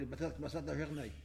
Saint-Christophe-du-Ligneron
Catégorie Locution